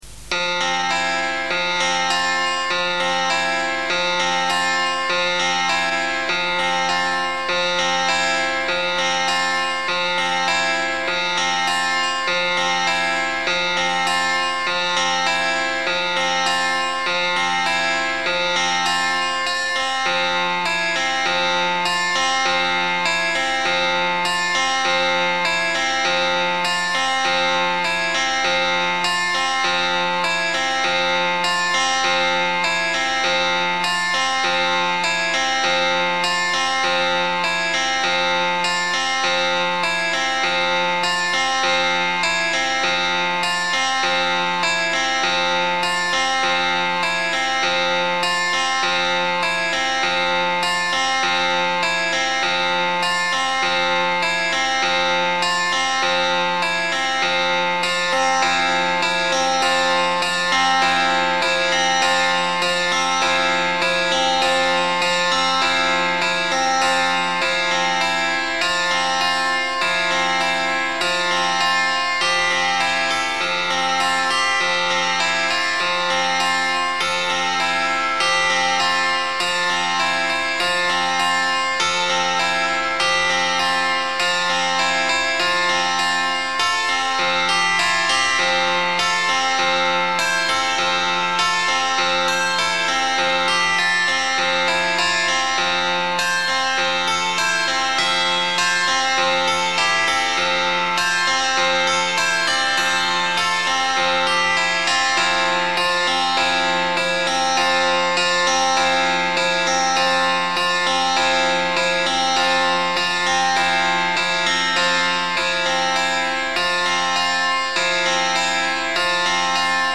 Eine weitere fast klassisch anmutende Arbeit